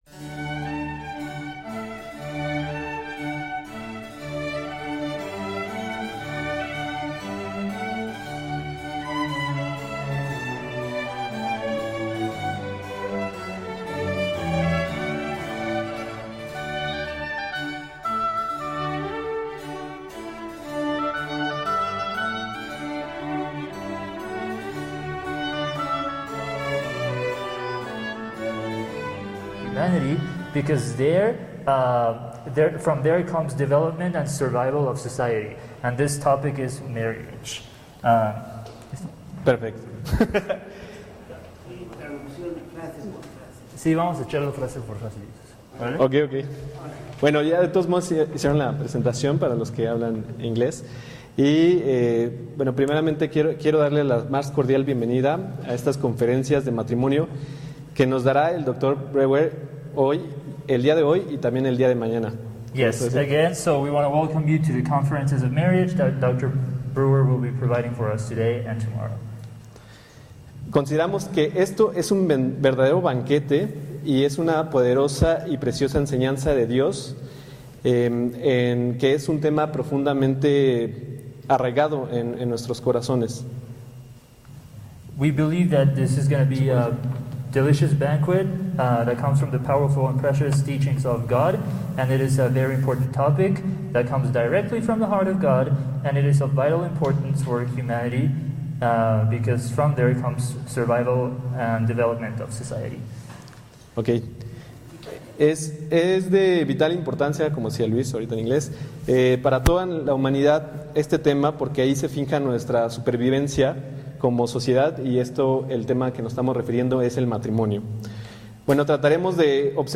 Conferencia Matrimonio 1